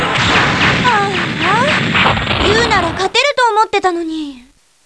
しかし、クリアできないときは得点によってモーリンのコメントが変わる。